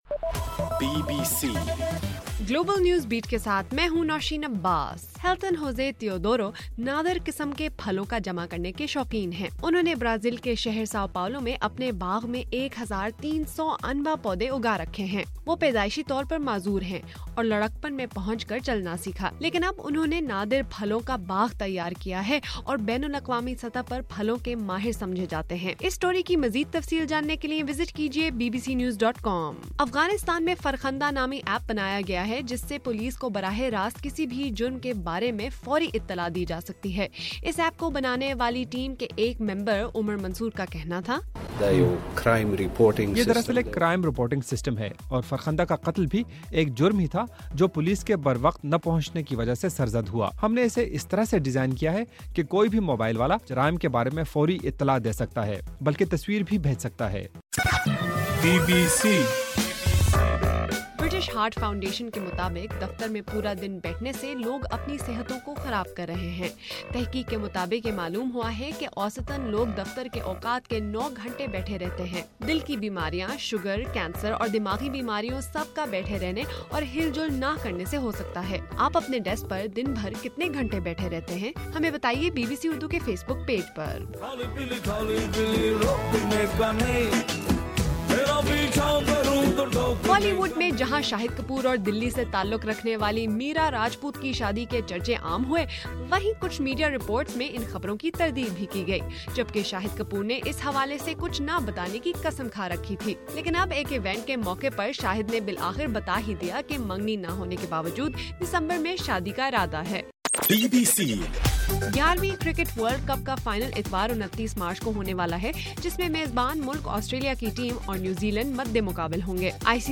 مارچ 27: رات 11 بجے کا گلوبل نیوز بیٹ بُلیٹن